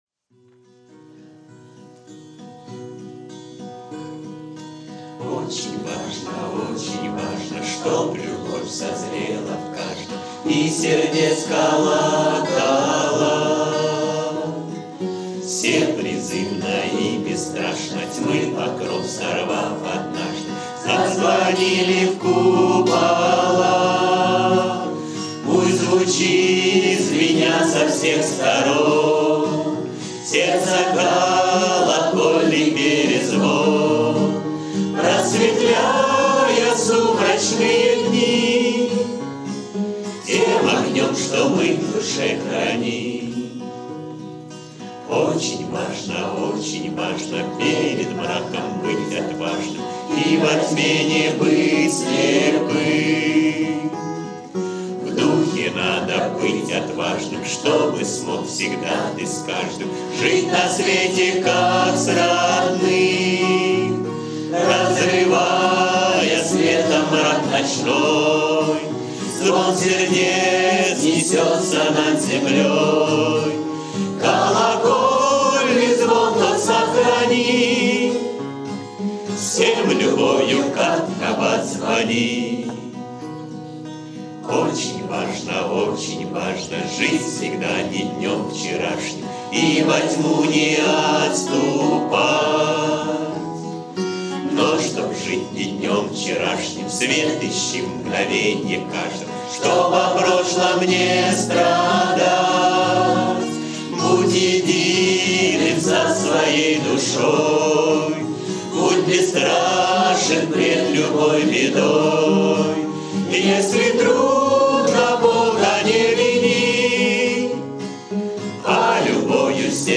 кавер-версия
акапелла